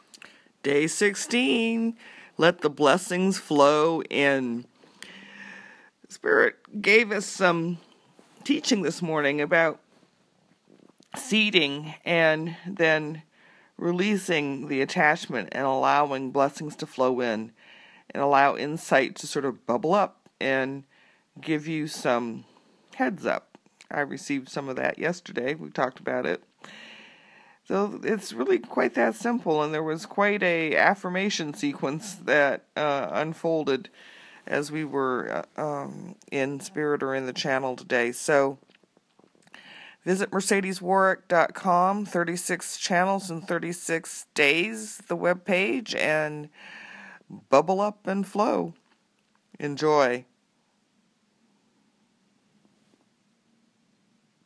Each Channel is RECORDED in the morning and then POSTED on the 36 CHANNELS IN 36 DAYS web page later in the day.